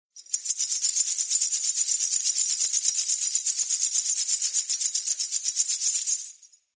Danza árabe, bailarina mueve un sujetador con monedas 01
agitar
moneda
Sonidos: Acciones humanas